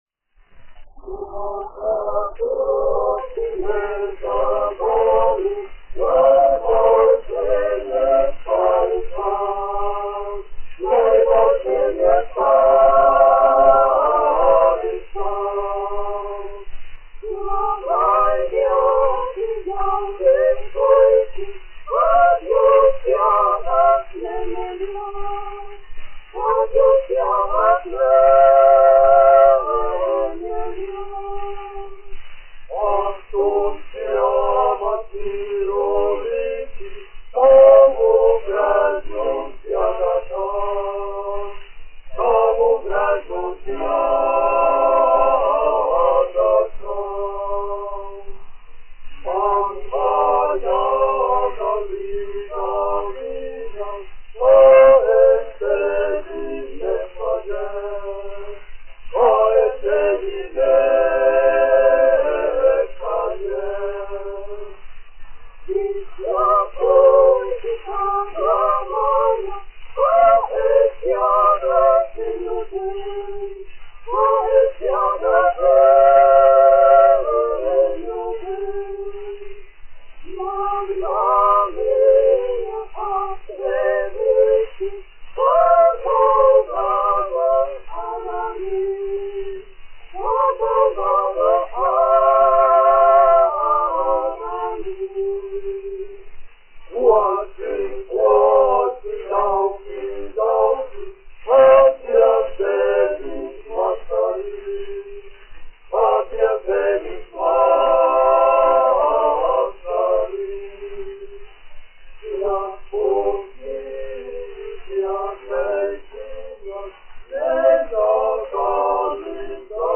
Emilis Melngailis, 1874-1954, aranžētājs
Rīgas Latviešu dziedāšanas biedrības jauktais koris, izpildītājs
1 skpl. : analogs, 78 apgr/min, mono ; 25 cm
Latviešu tautasdziesmas
Kori (jauktie)
Latvijas vēsturiskie šellaka skaņuplašu ieraksti (Kolekcija)